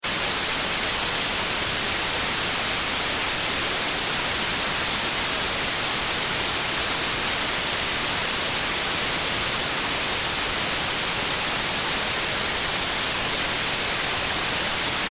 Monophone Klänge:
NOISE.mp3